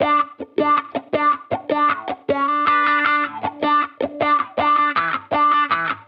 Index of /musicradar/sampled-funk-soul-samples/79bpm/Guitar
SSF_StratGuitarProc1_79E.wav